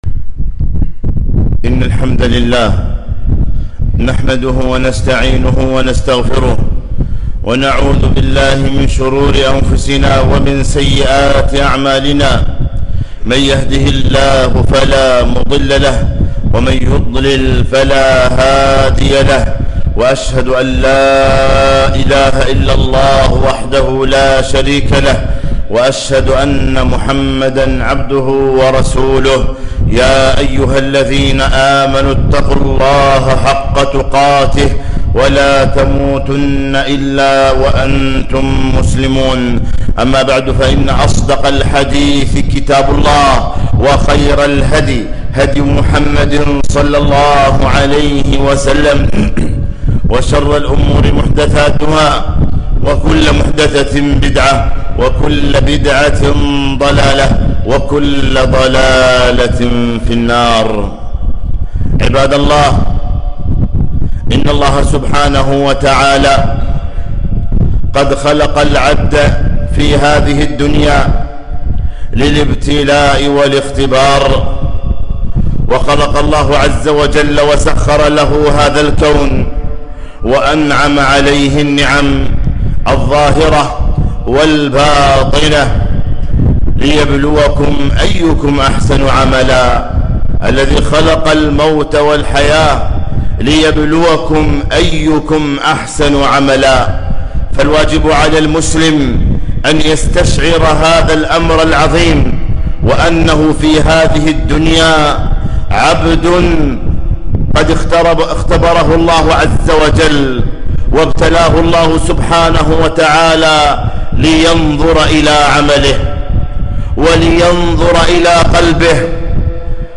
خطبة - طهارة القلوب قبل رمضان